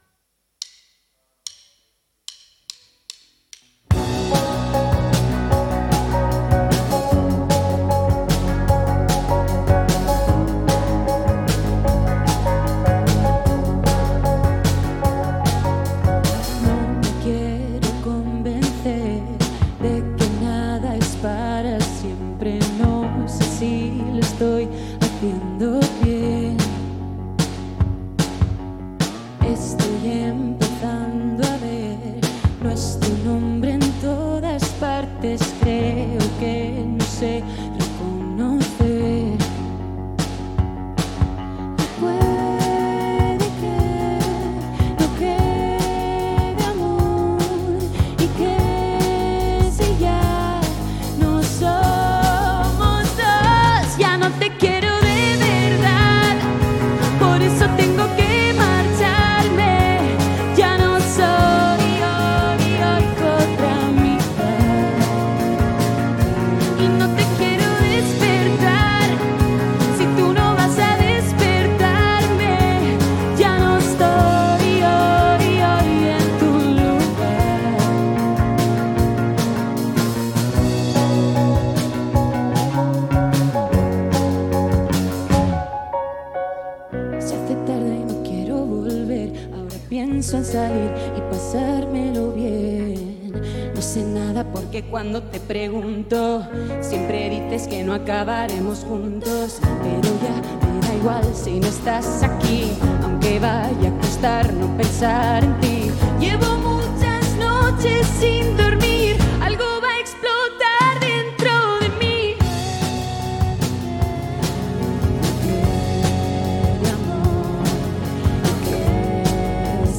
Spanish Indie with a luxurious sprinkling of Bedroom Pop.